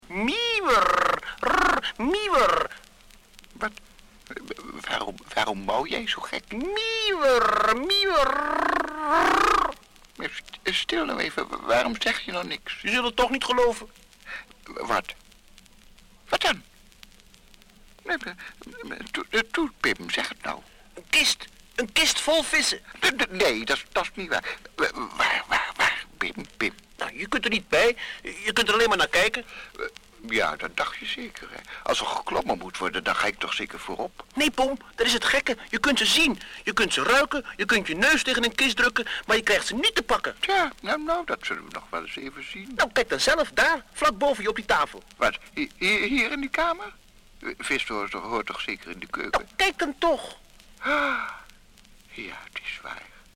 Hoorspel en vertellingen
Ton Lensink en onbekende acteurs